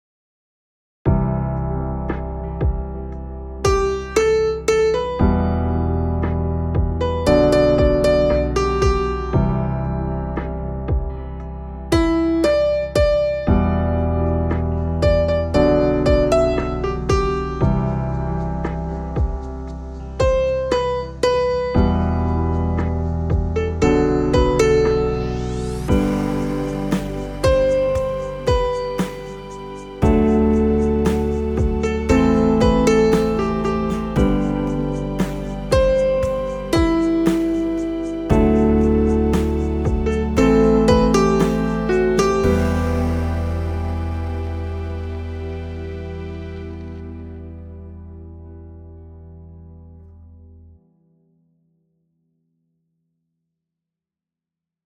guide for tenor